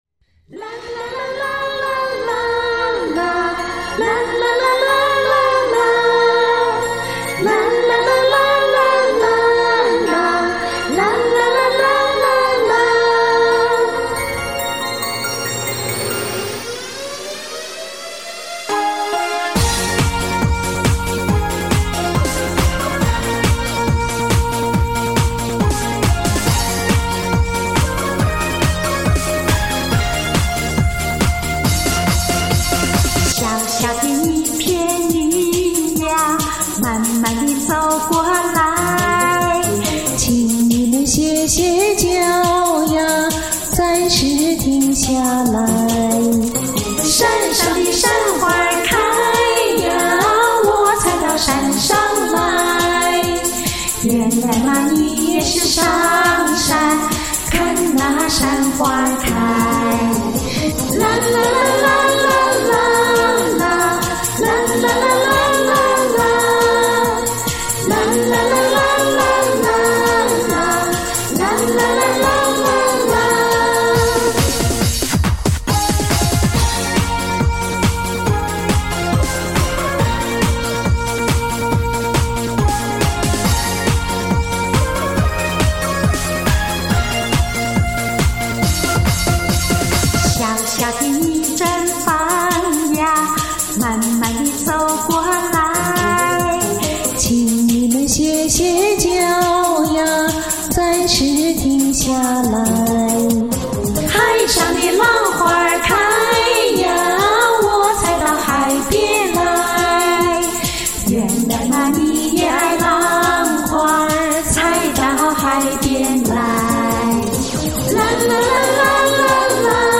欢快清新，你们唱得好听！
很甜，很欢快，听了感到自己年轻了。
甜美动听，好美的歌声！
节奏强劲，时代感强
欢快悠扬动听！